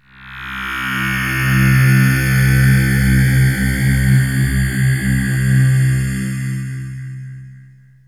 AMBIENT ATMOSPHERES-4 0003.wav